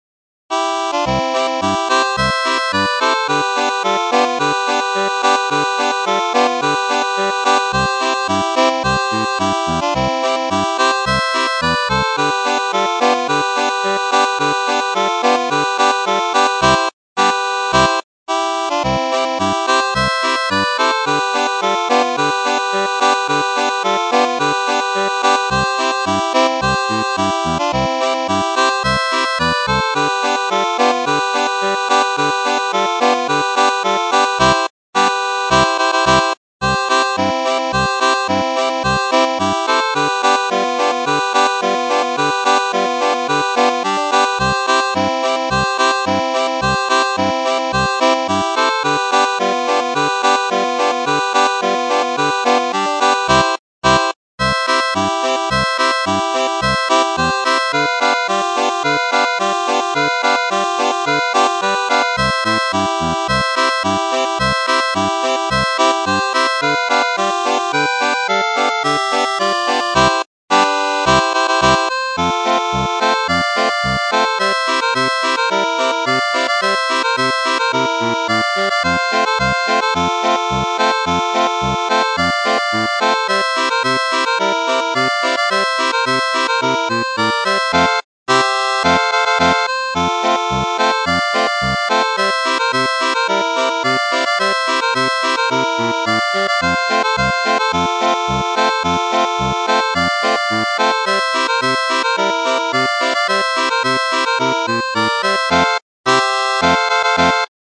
Ohne Text